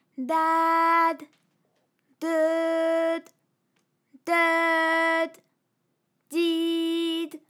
ALYS-DB-001-FRA - First, previously private, UTAU French vocal library of ALYS
dadedeudid.wav